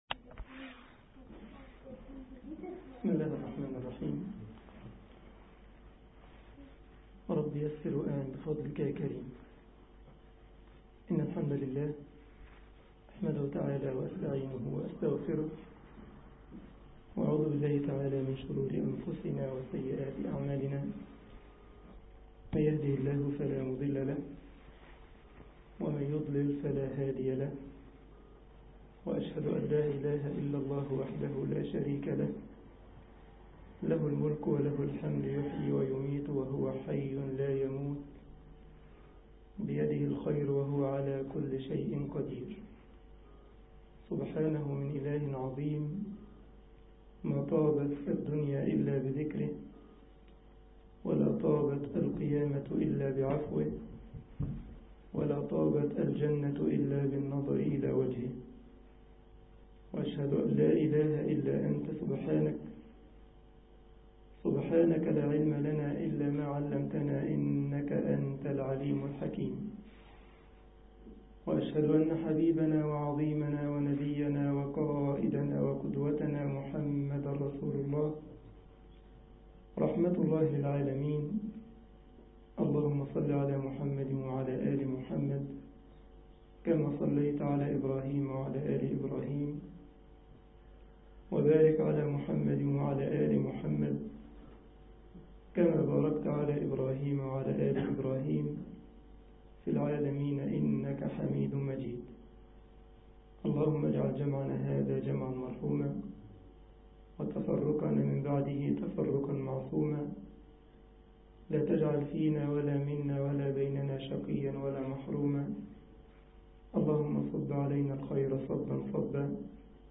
مسجد شتوتغارت ـ ألمانيا محاضرة